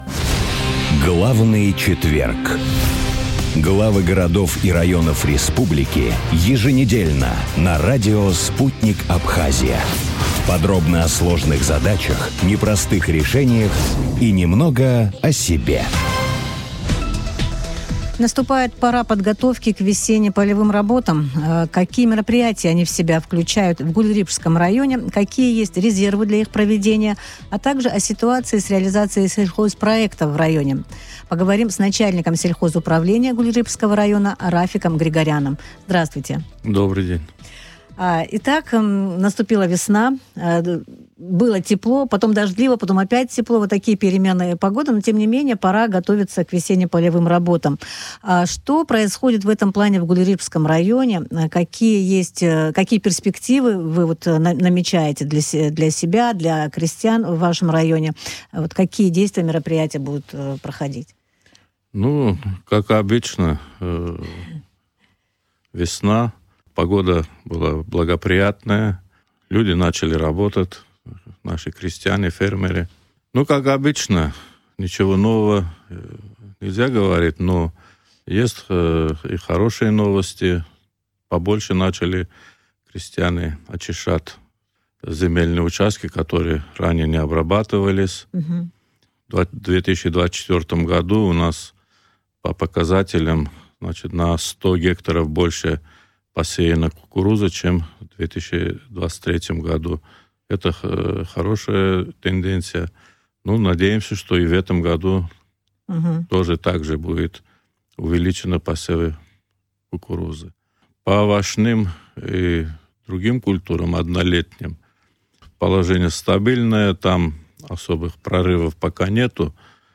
О ситуации в сельском хозяйстве Гулрыпшского района, о подготовке к весенне-полевым работам в эфире радио Sputnik